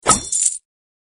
Sound_GetCoins.mp3